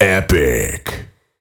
Фразы после убийства противника